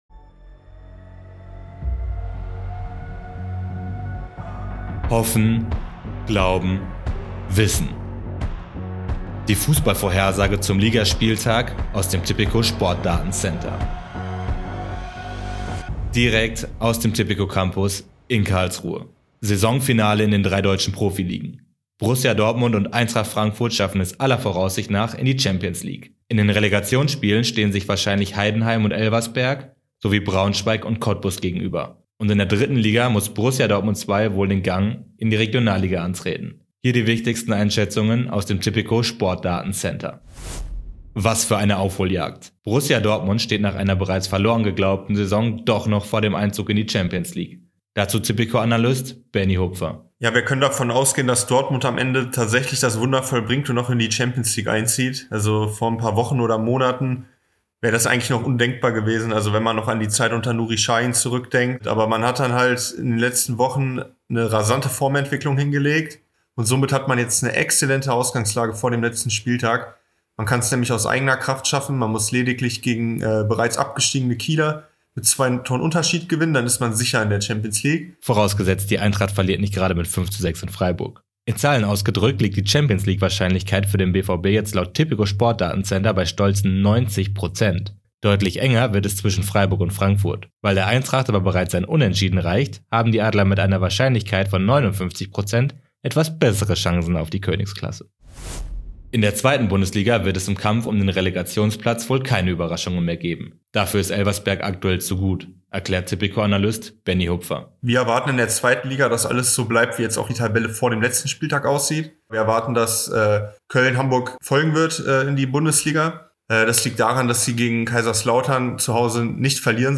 Newscast Die Pressemeldung im Audio-Newscast: Hoffen – Glauben – Wissen Das Tipico Sportdatencenter liefert datengestützte Prognosen und kennt […]